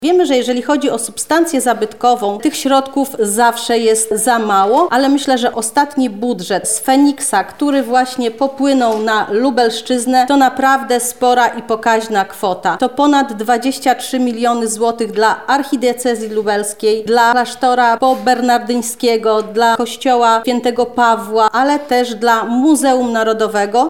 PSL konferencja
– mówi Bożena Żelazowska, sekretarz stanu w Ministerstwie Kultury i Dziedzictwa Narodowego.
PSL-konferencja.mp3